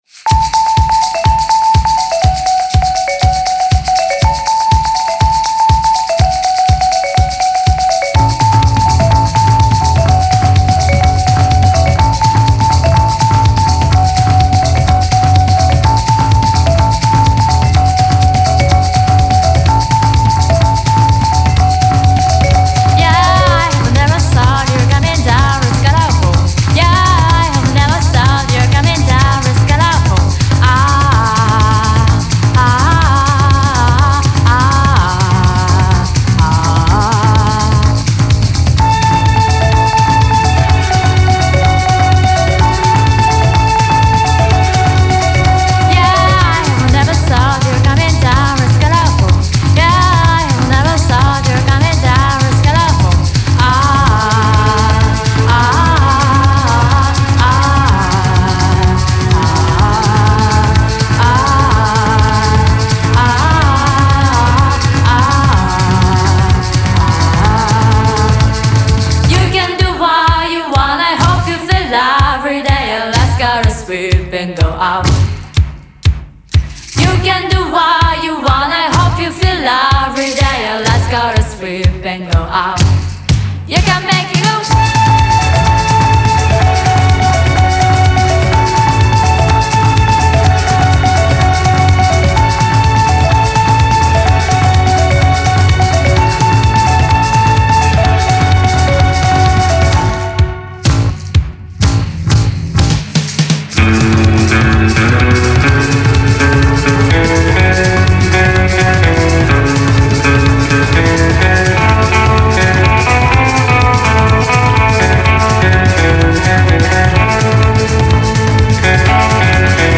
Surfing into earshot on a tide of xylophone chimes
an all-girl quartet